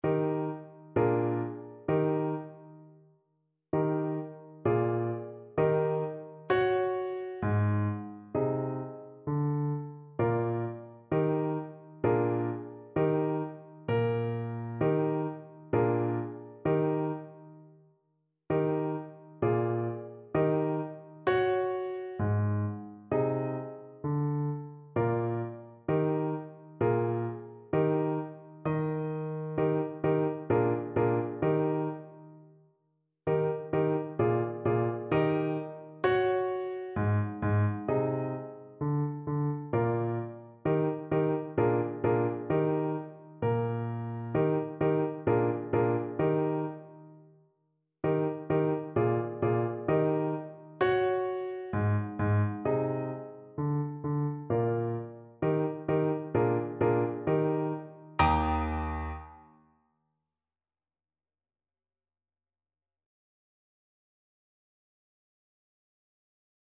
Clarinet Traditional
Moderato
4/4 (View more 4/4 Music)
Traditional (View more Traditional Clarinet Music)